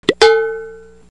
Metal Sound Effects MP3 Download Free - Quick Sounds